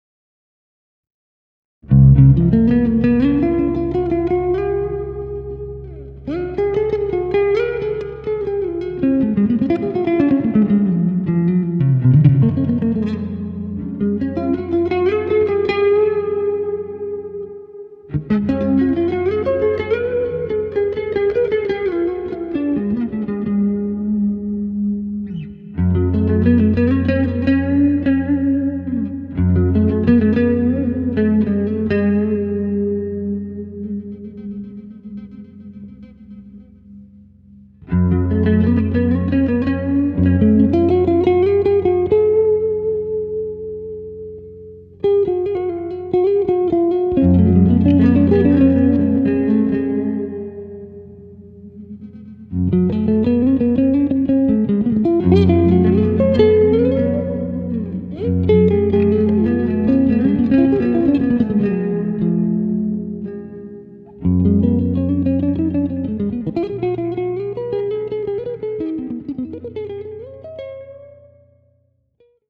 Нажмите для раскрытия... даже удивительно что такой яркий и звонкий звук на старых струнах... прикреплю свое звучание... тоже на неновых струнах.. (кусочек) тут у меня глуховато звучит правда... наверно потому что на нековом датчике все играл и писал.. ну тоже как бы дэмка... и вот другой вариант звучания...